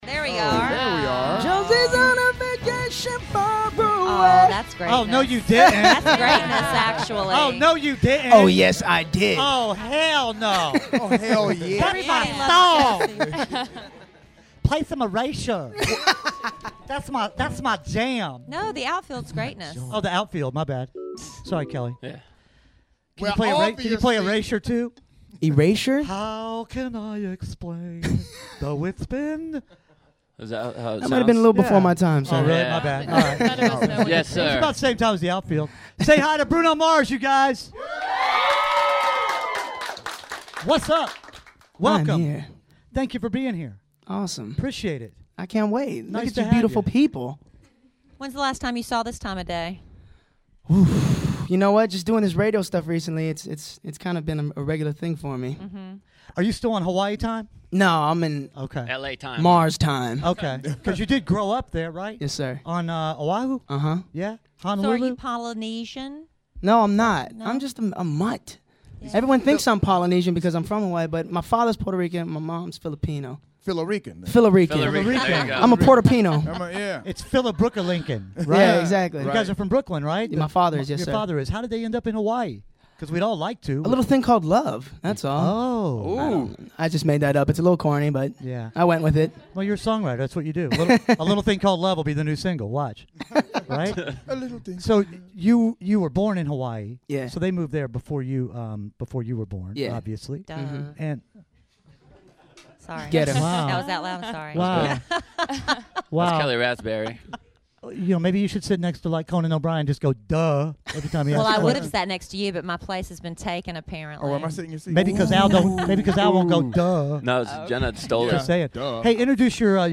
Before anyone really knew the name Bruno Mars, we had him in the Canalside Lounge... just chatting and playing music!